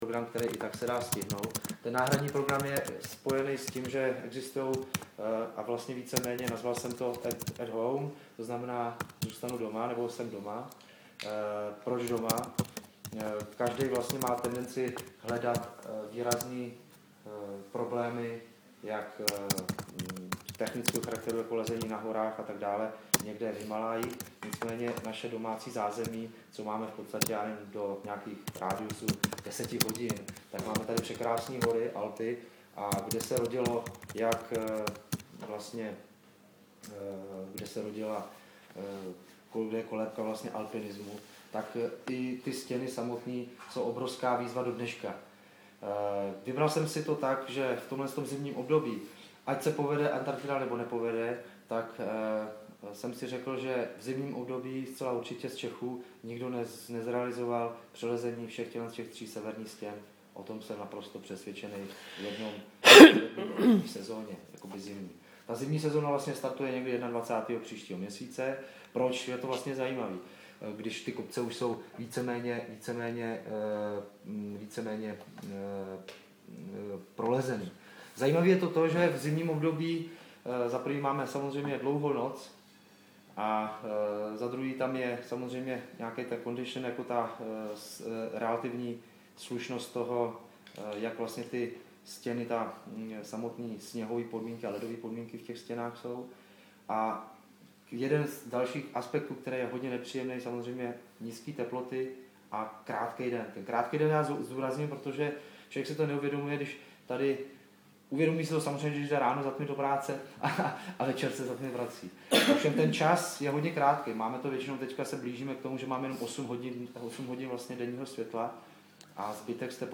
Tisková konference